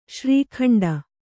♪ śrī khaṇḍa